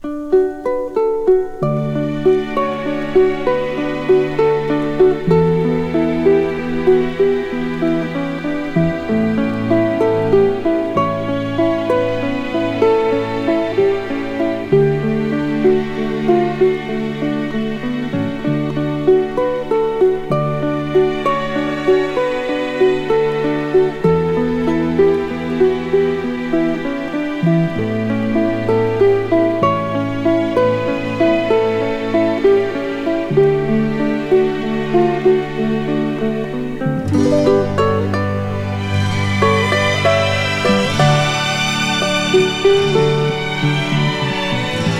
イージーリスニング・スタンダードとも言える楽曲がウクレレとストリングスで彩り豊かに。
Jazz, Pop, Easy Listening　USA　12inchレコード　33rpm　Stereo